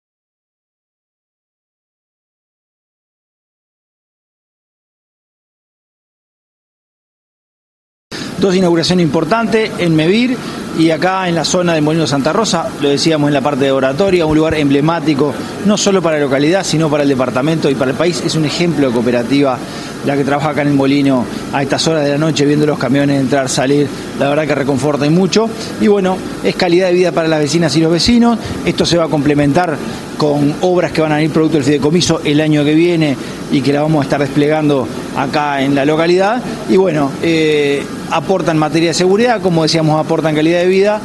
Se realizó una nueva inauguración de luces en Santa Rosa, que contó con la presencia del Secretario General de la Intendencia de Canelones Dr. Esc. Francisco Legnani.